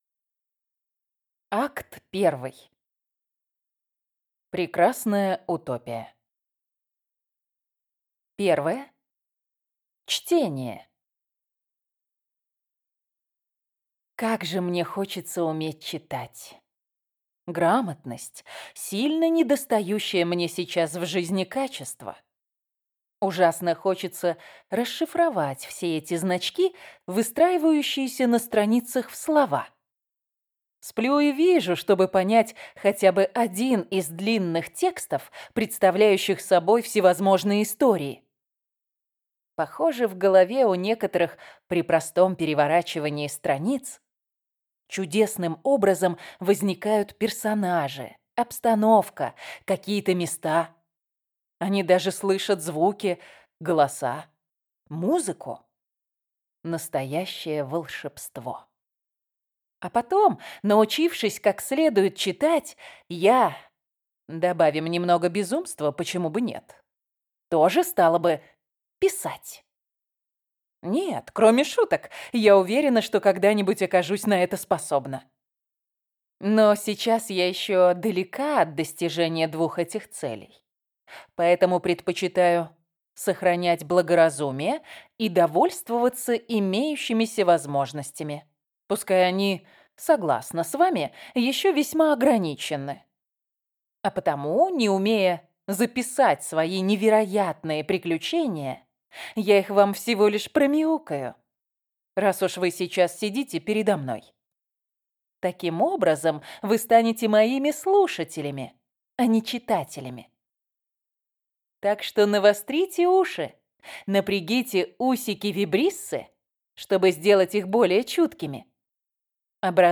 Аудиокнига Ее величество кошка | Библиотека аудиокниг